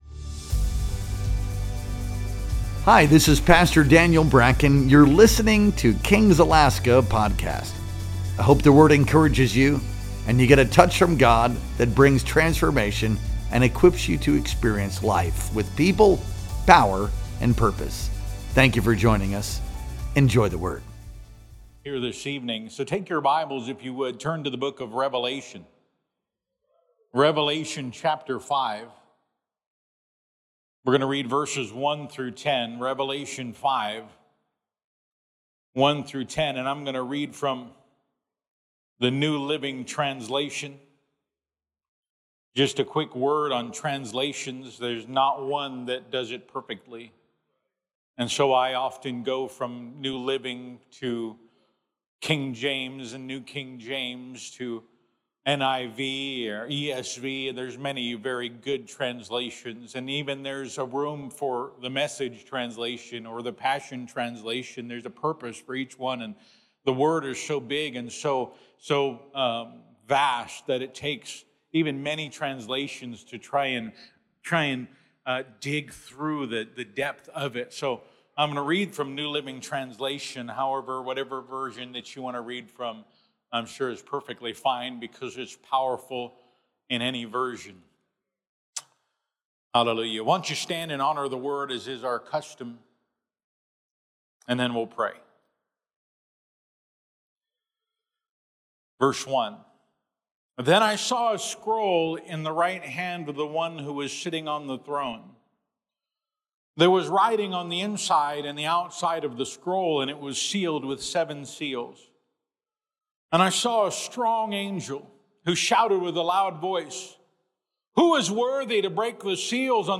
Our Wednesday Night Worship Experience streamed live on September 3rd, 2025. Experience life with people, power, and purpose.